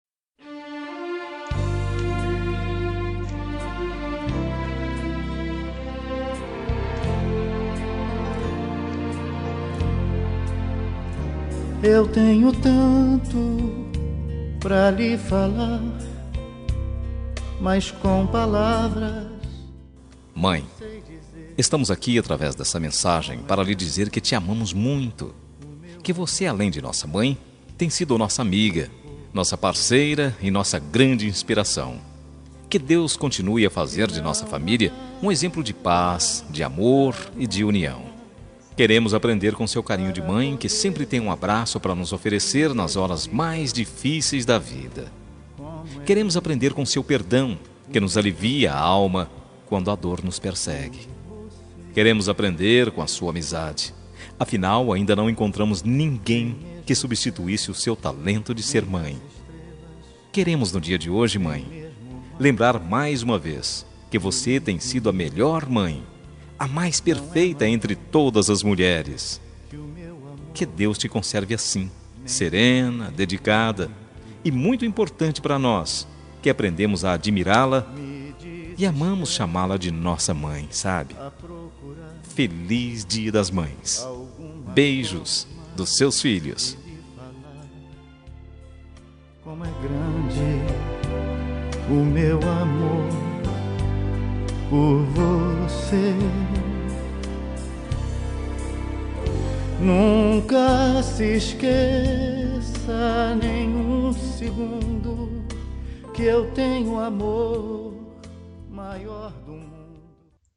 Dia das Mães – Para minha Mãe – Voz Masculina – Plural – Cód: 6523